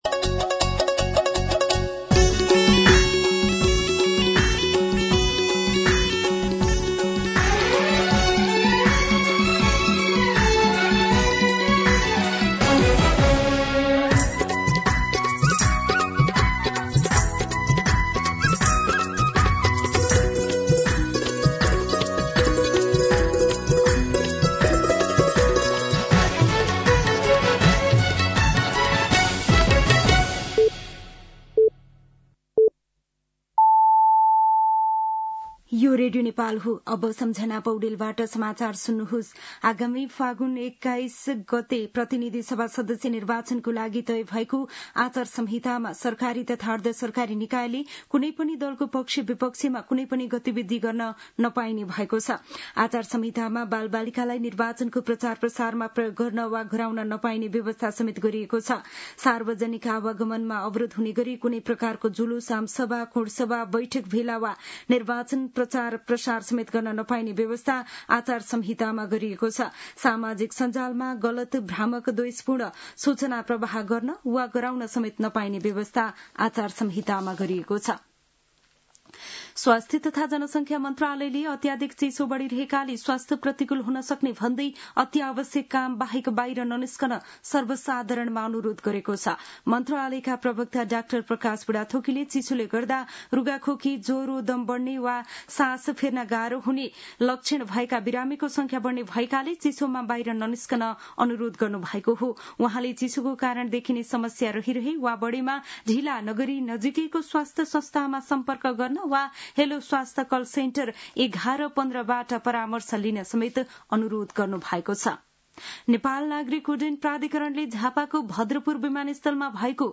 मध्यान्ह १२ बजेको नेपाली समाचार : २० पुष , २०८२
12-pm-Nepali-News.mp3